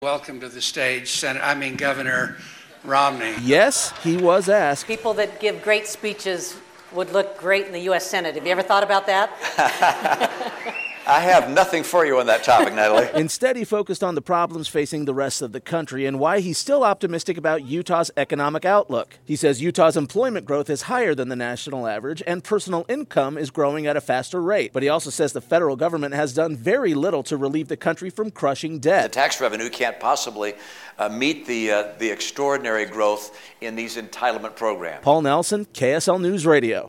However, he gave the keynote address at the Salt Lake Chamber 2018 Economic Outlook, and told Utahns there are reasons to be optimistic about the future.